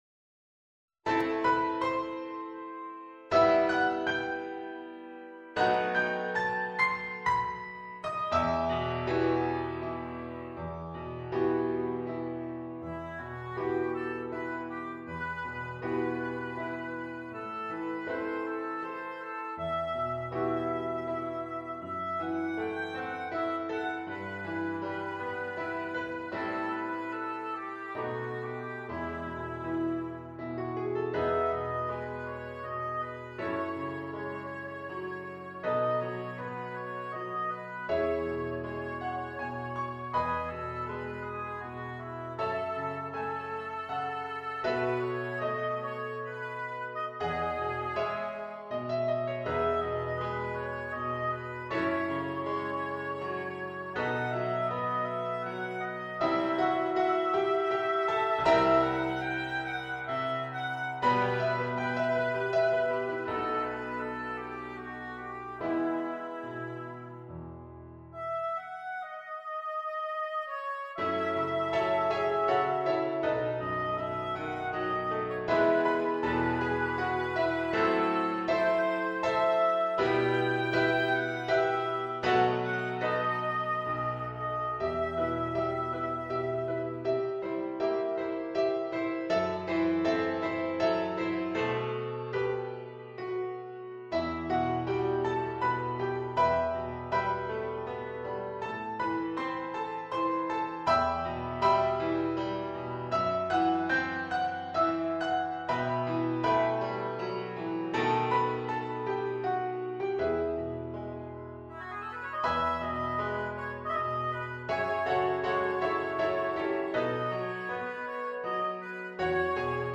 arranged for oboe and piano